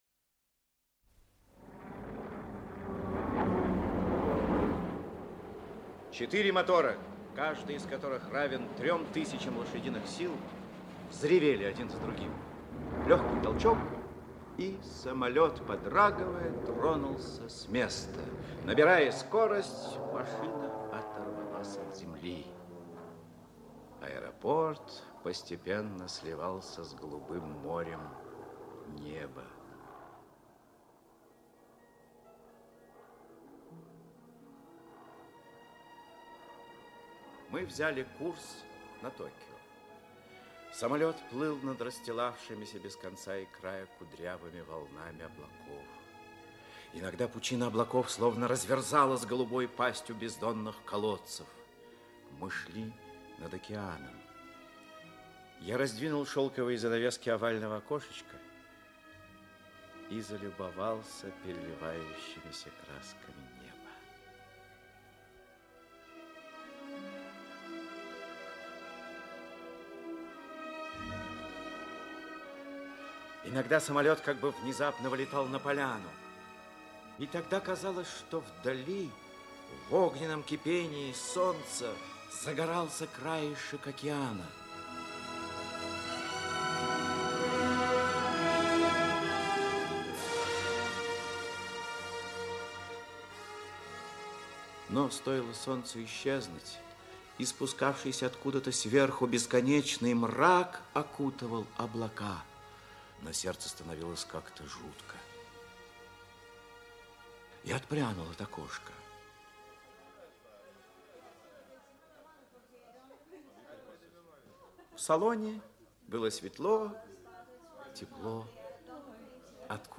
Аудиокнига Мина взорвется в 6.50 | Библиотека аудиокниг
Aудиокнига Мина взорвется в 6.50 Автор Аскад Мухтар Читает аудиокнигу Актерский коллектив.